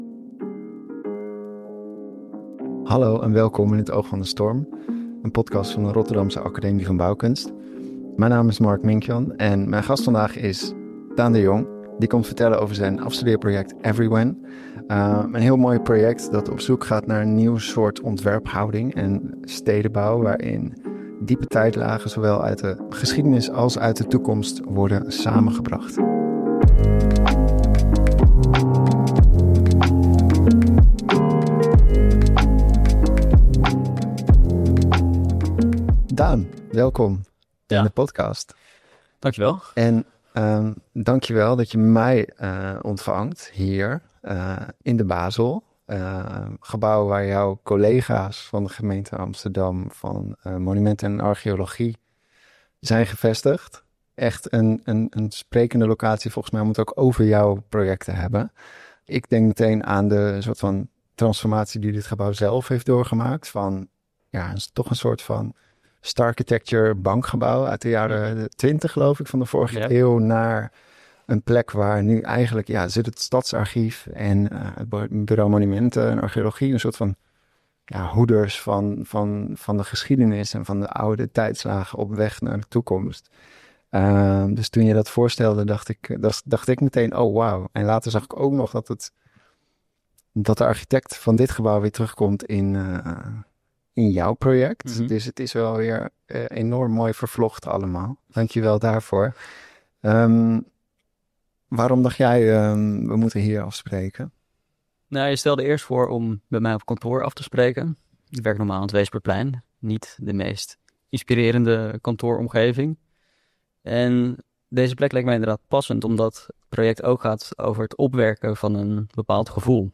Gesprekken over ruimtelijk ontwerp in de klimaatcrisis.